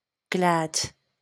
IPA/ɡlæd/, SAMPA/ɡl{d/
wymowa amerykańska?/i